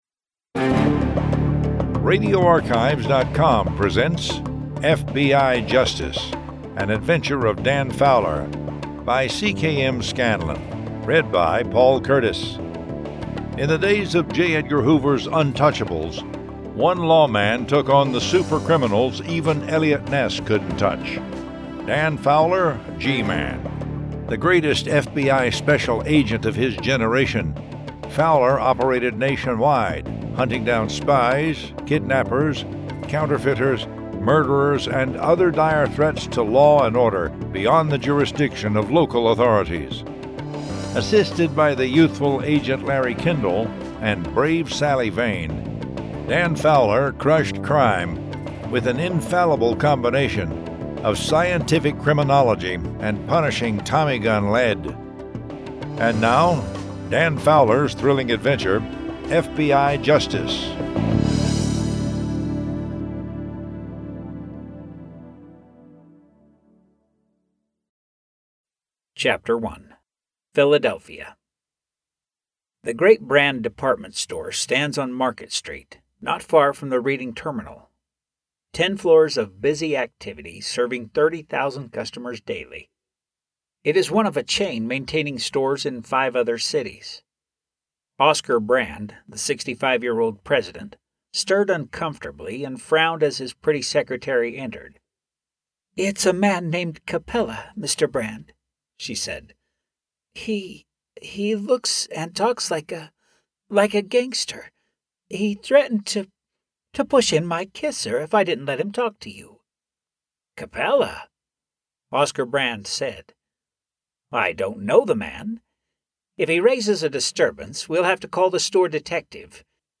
Dan Fowler: G-Man Audiobook